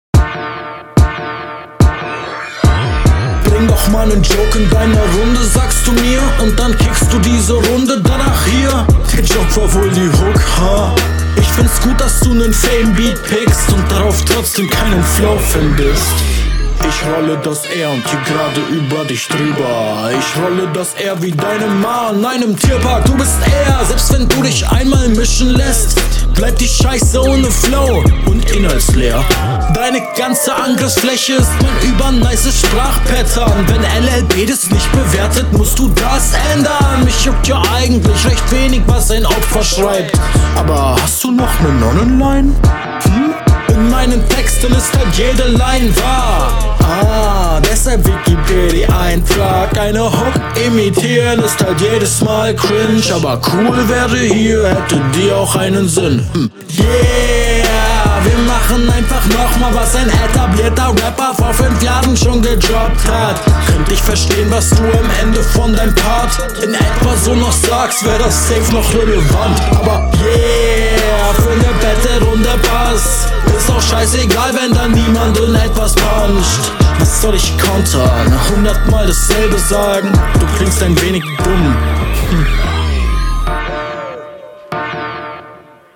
Audio kinda arsch, aber sonst dope